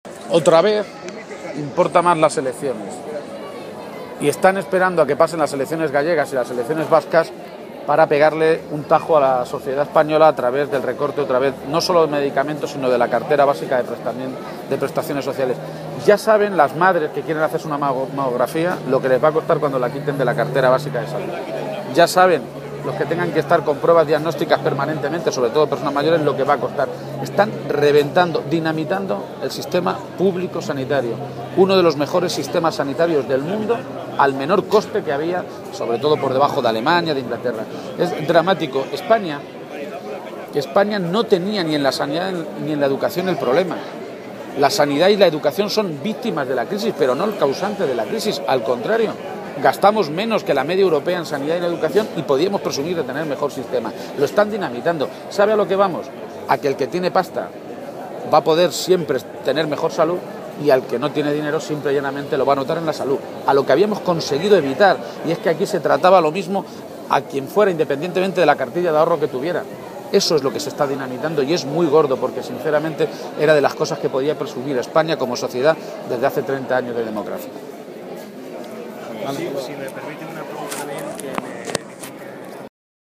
García-Page ha hecho estas manifestaciones hoy en Guadalajara, donde ha estado para asistir a las Ferias de la ciudad y compartir una comida solidaria con militantes y simpatizantes socialistas.